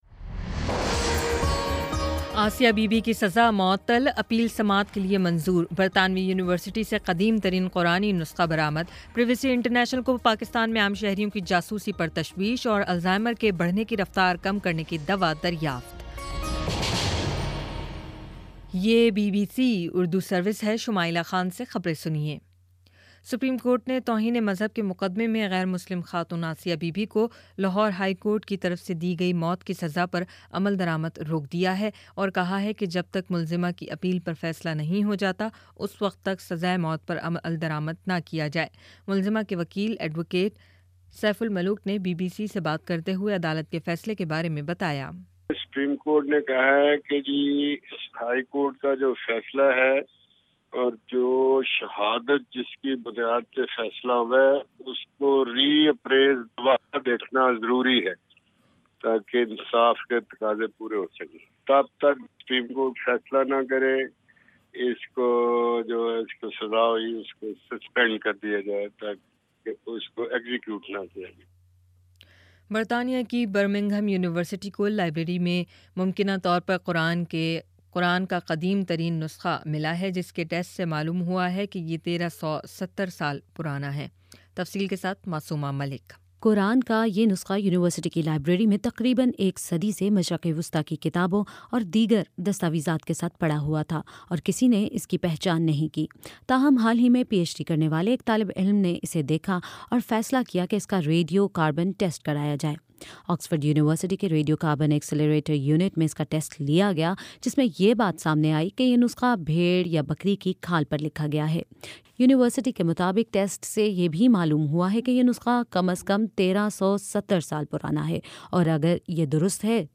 جولائی 22: شام سات بجے کا نیوز بُلیٹن